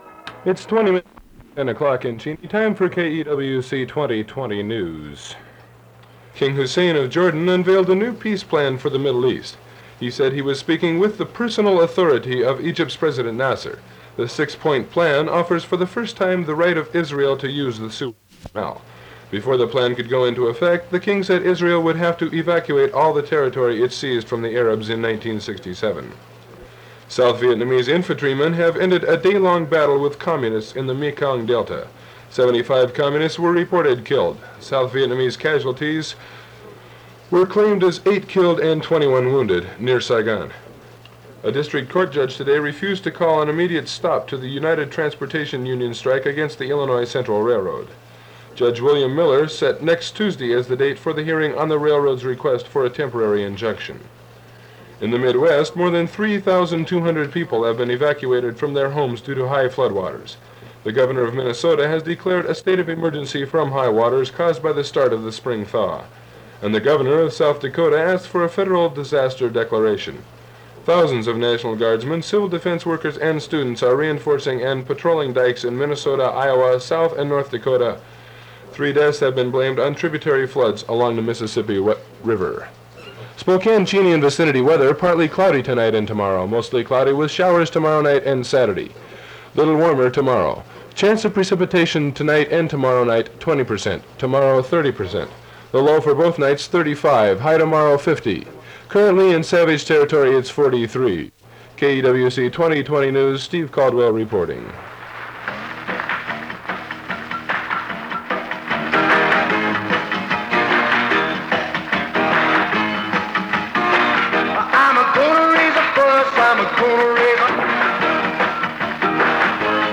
April 1969 KEWC aircheck (2:12)
Your KEWC aircheck cut off going into a great tune by Iron Butterfly.
Yup…that’s all there was on that tape from years ago…in fact I edited out a great portion of that 5-minute newscast…it was pretty bad.